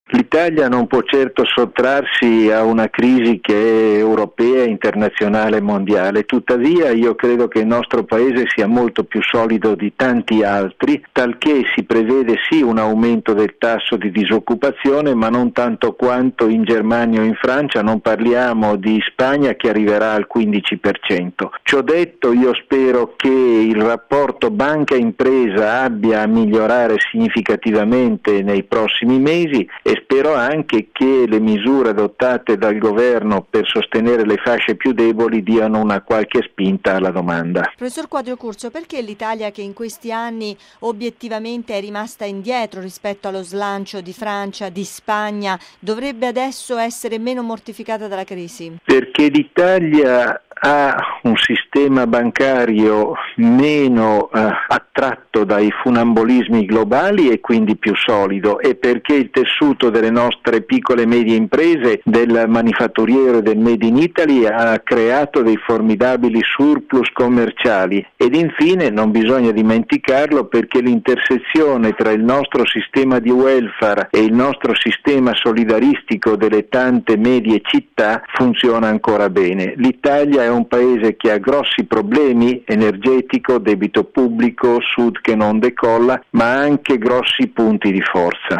l’economista